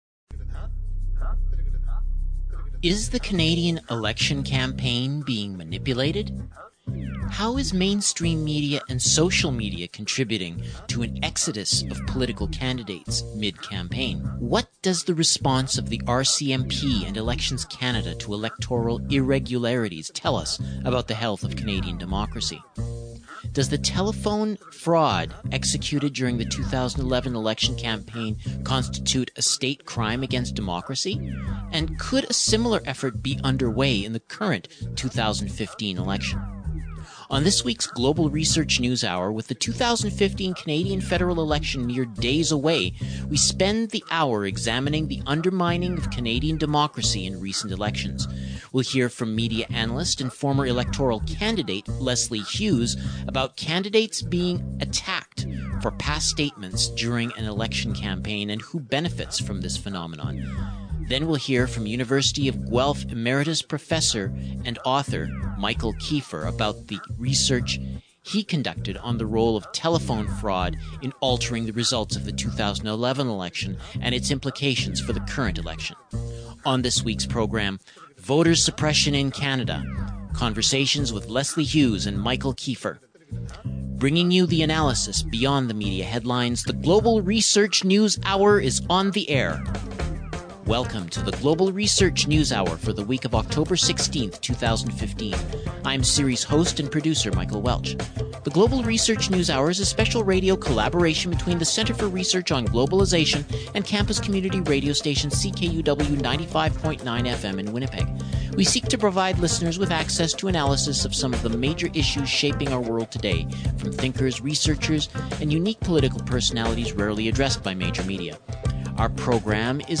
Voter Suppression in Canada: Conversations